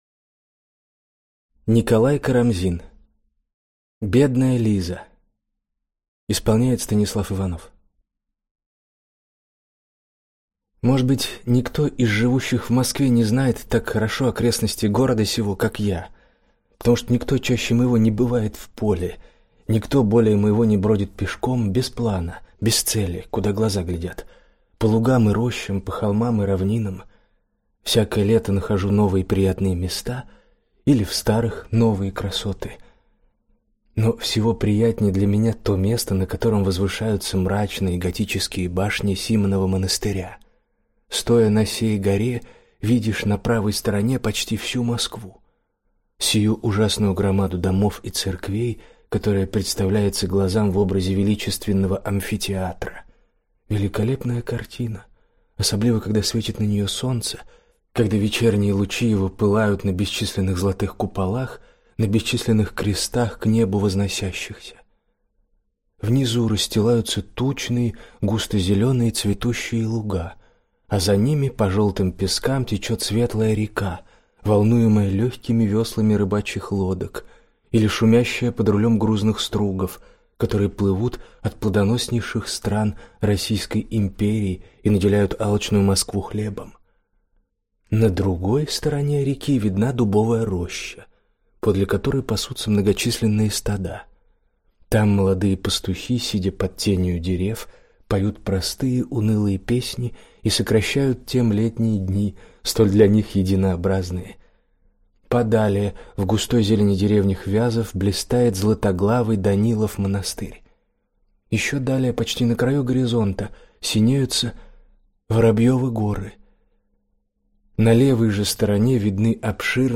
Аудиокнига Бедная Лиза | Библиотека аудиокниг